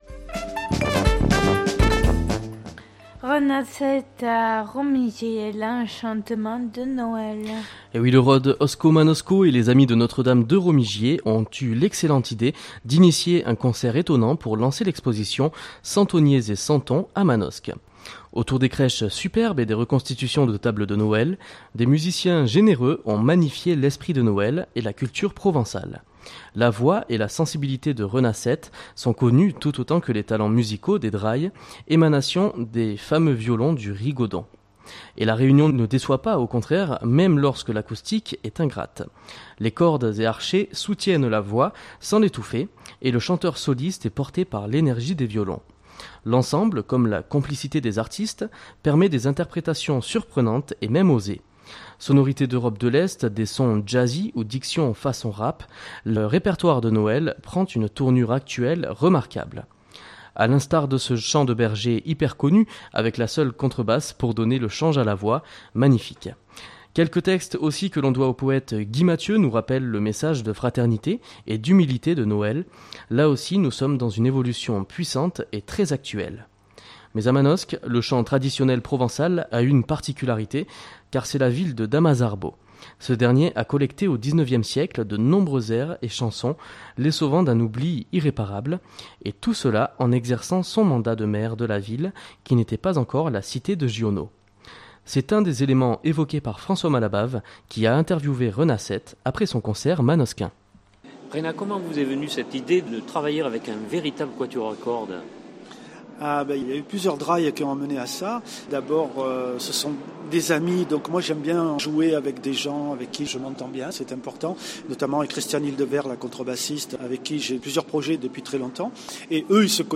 Et la réunion ne déçoit pas au contraire même lorsque l’acoustique est ingrate. Les cordes et archets soutiennent la voix sans l’étouffer et le chanteur soliste est porté par l’énergie des violons.
Sonorités d’Europe de l’Est, des sons jazzy ou diction façon rap, le répertoire de Noël prend une tournure actuelle remarquable. A l’instar de ce chant de bergers hyper connu avec la seule contrebasse pour donner le change à la voix.